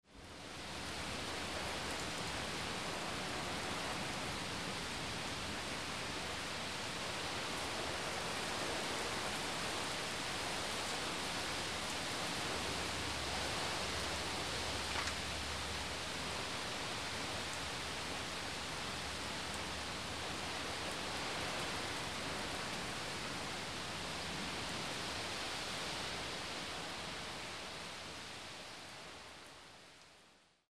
rain_thunder05.mp3